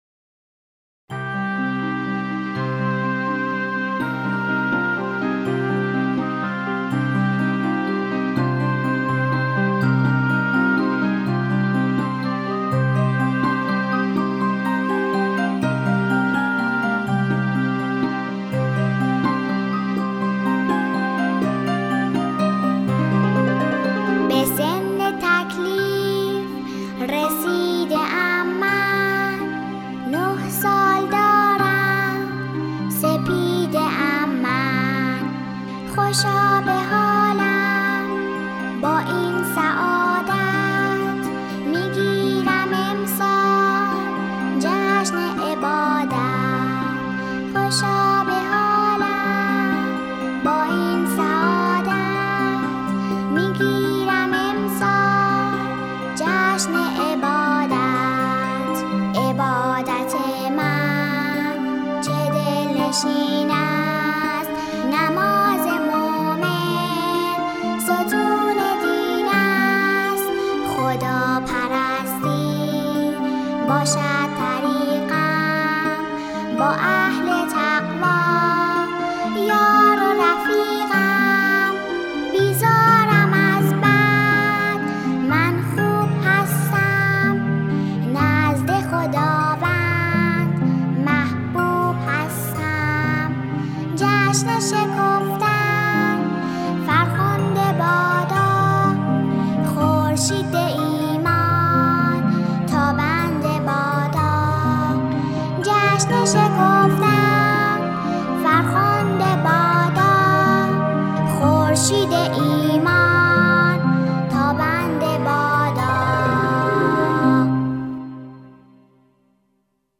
(سرود، جشن تکلیف)